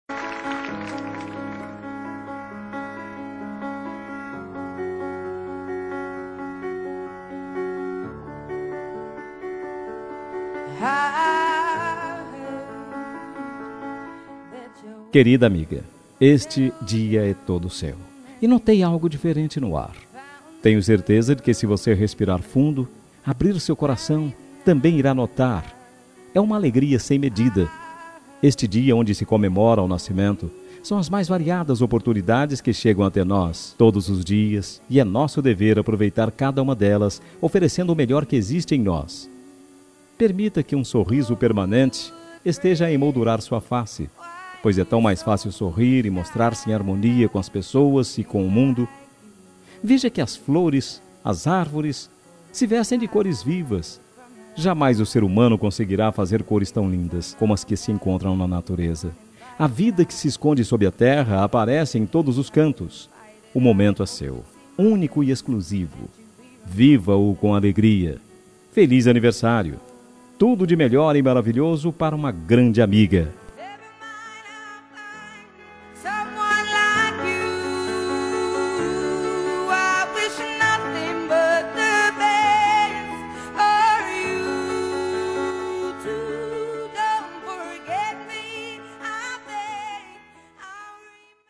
Telemensagem Aniversário de Amiga – Voz Masculina – Cód: 202060